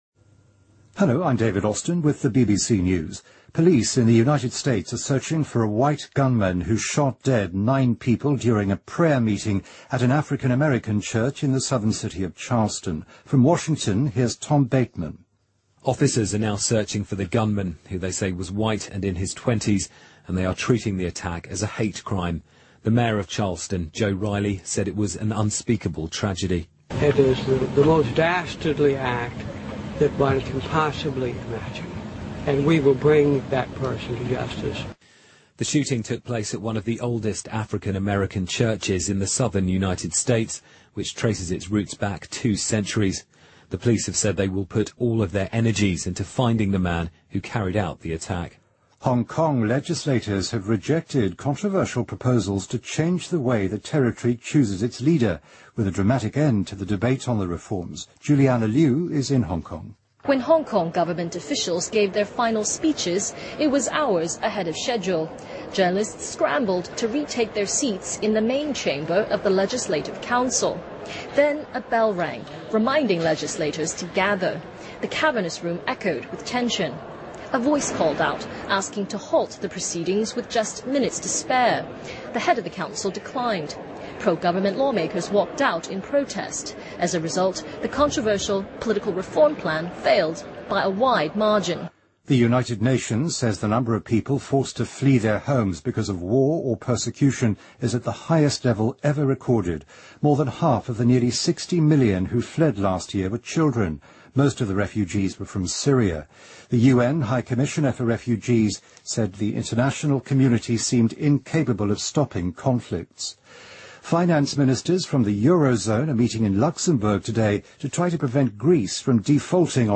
BBC news,香港立法会否决政改方案